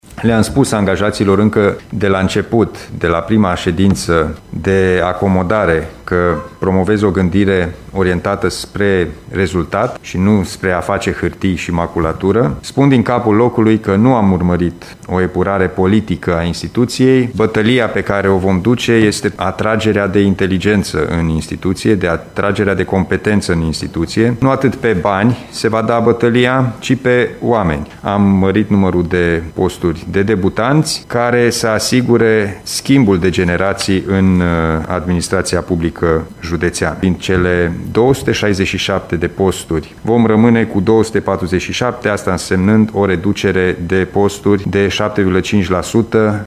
Conducerea Consiliului Județean vrea să renunțe la 20 dintre angajați, iar noua structură ar urma să elimine stresul în rândul angajaților, spune președintele CJ Timiș, Alin Nica.